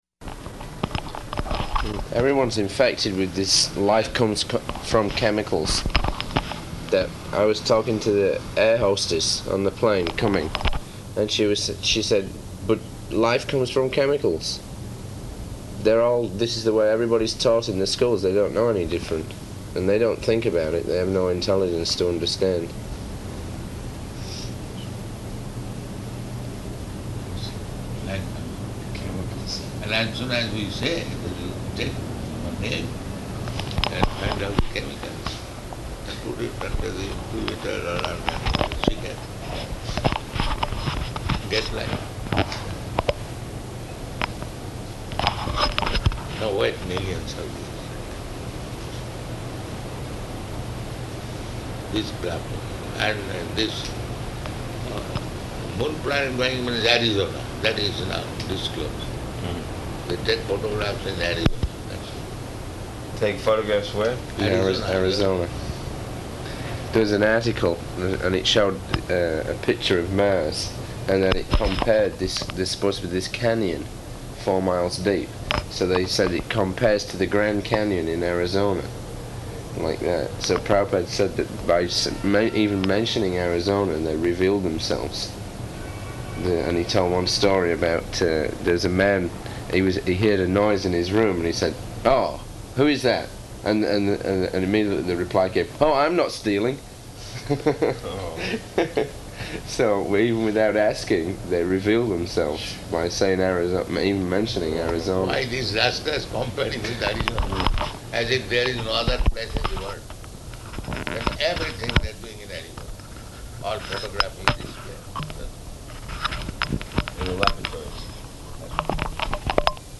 -- Type: Walk Dated: July 10th 1976 Location: New York Audio file
[in room before walk]